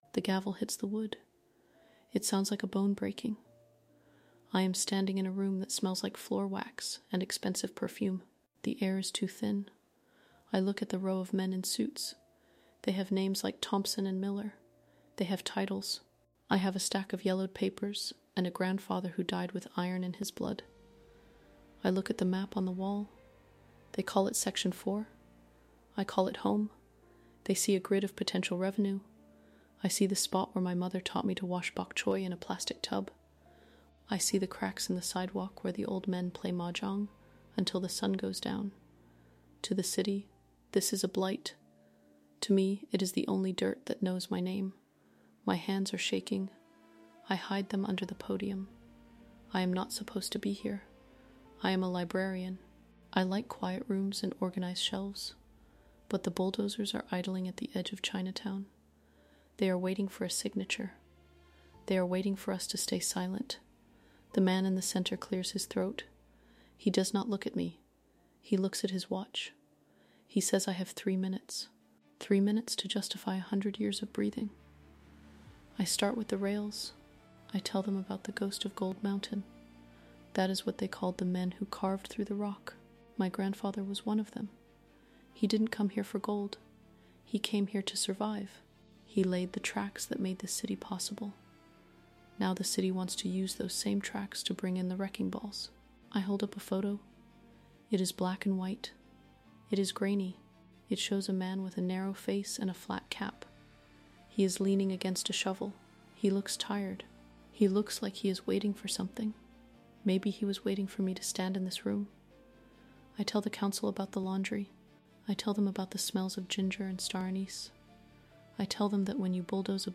This first-person storytelling experience dives into the emotional reality of protecting a legacy that the world wants to pave over.